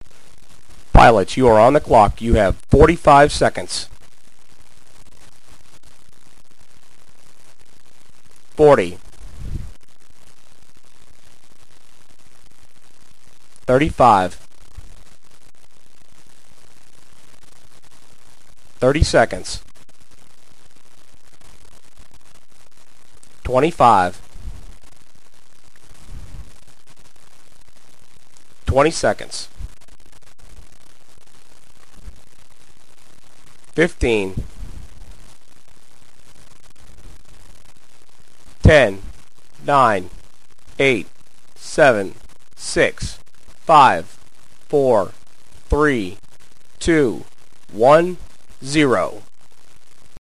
CountdownStart_45_Seconds.mp3